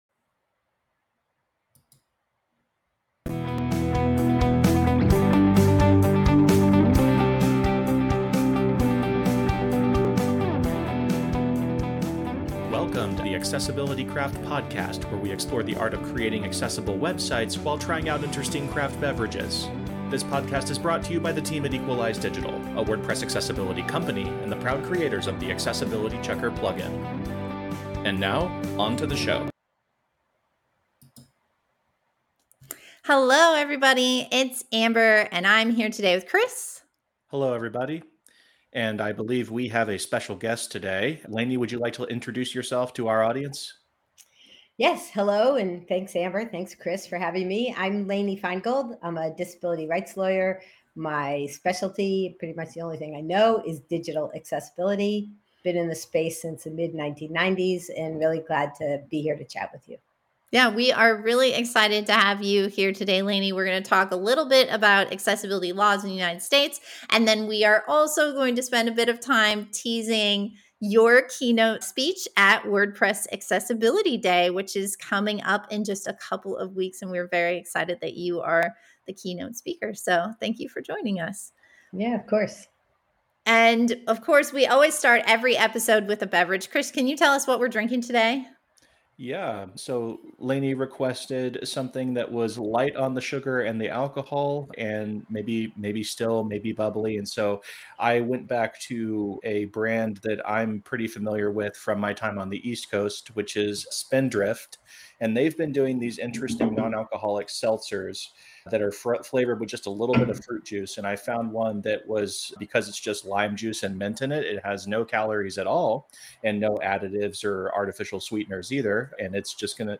Welcome to episode 86 of the Accessibility Craft Podcast, where we explore the art of creating accessible websites while trying out interesting craft beverages. This podcast is brought to you by the team at Equalize Digital, a WordPress accessibility company, and the proud creators of the Accessibility Checker plugin.